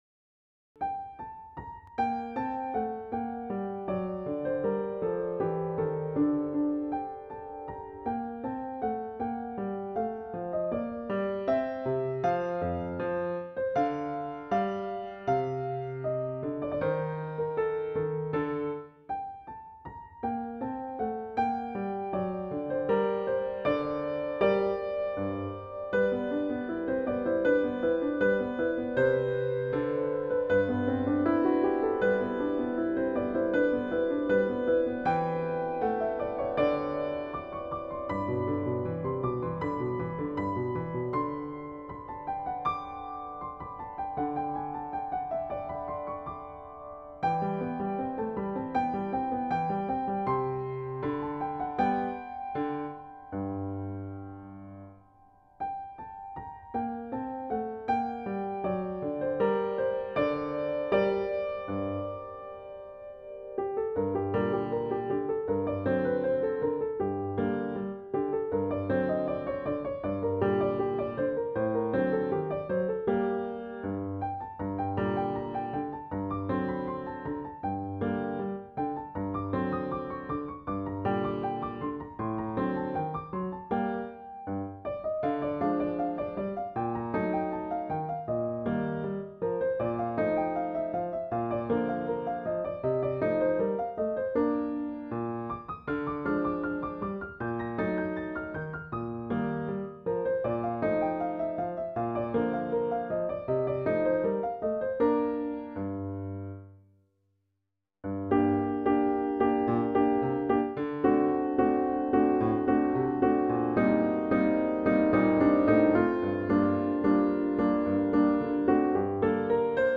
Variations for piano in g-minor on an early Beethoven draft
Especially the second last - a little fugato - with some more more daring harmonies do interest me -  do they fit?
Most pleasant to listen to.